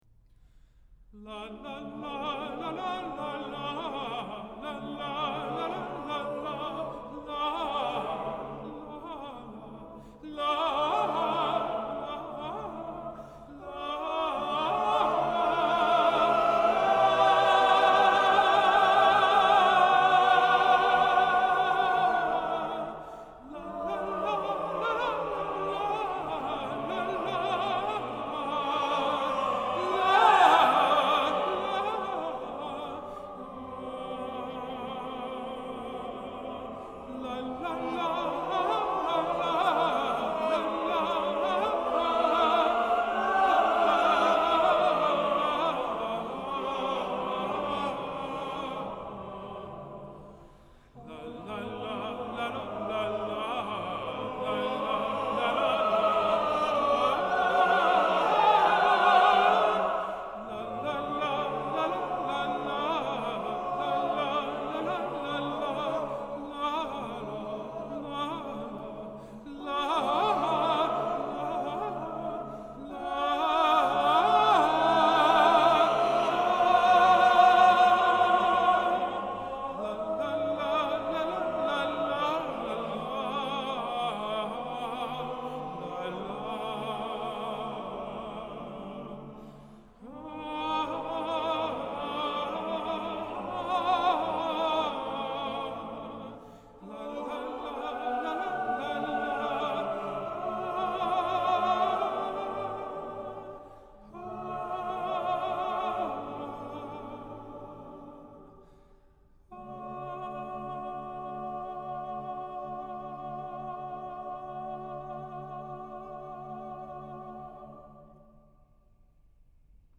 During the period between the composition and premiere of The Song of the High Hills, Delius would once again turn to dramatic vocalization in the two wordless songs To Be Sung of a Summer Night on the Water (1917).
The second of the two songs is contrasting in its optimistic cheer; a lively solo tenor presents the song’s main thematic idea.
Both the theme from Appalachia and the second song are in a major key and have a similar melodic contour—the beginning of both melodies features an arpeggiated second-inversion tonic triad.